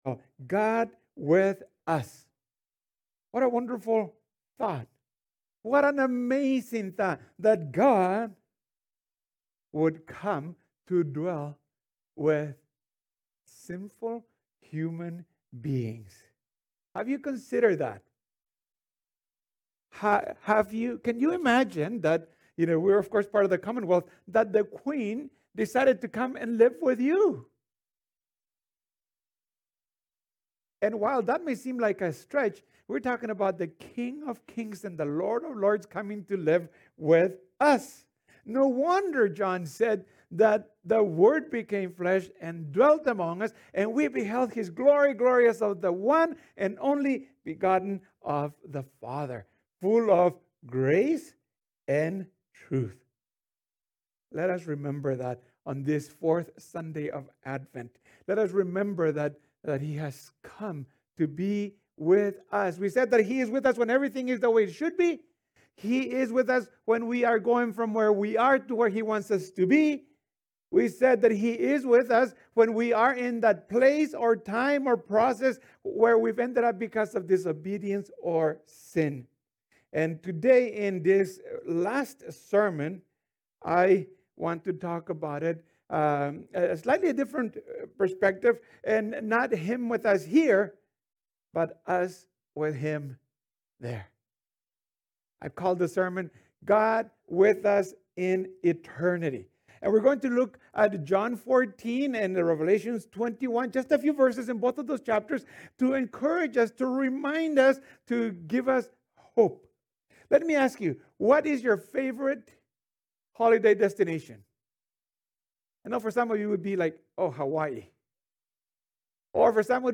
This is the fourth and final sermon of the series.